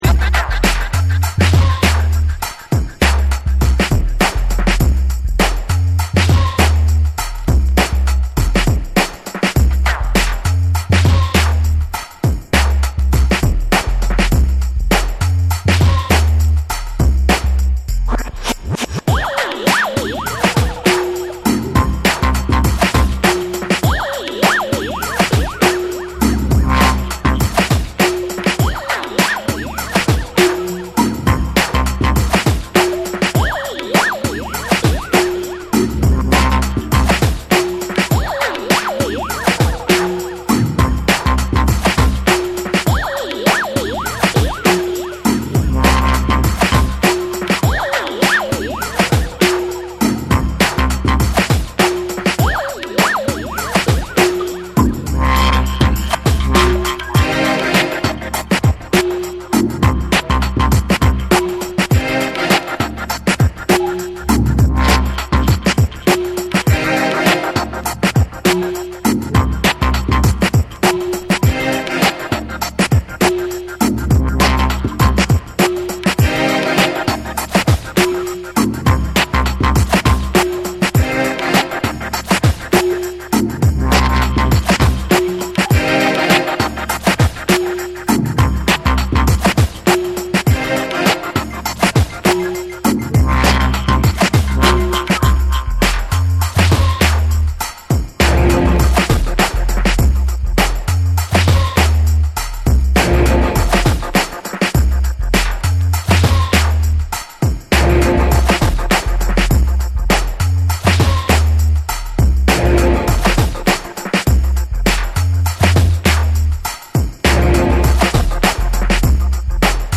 深みのあるベースラインとエコーがかったサウンドを特徴とする、クロスオーバーなダブ・ブレイクビーツ。
ダブ、ダウンテンポ、トリップホップが交差する独創的な音像は、リスニング用途にも、DJプレイにも適した作品。
BREAKBEATS / REGGAE & DUB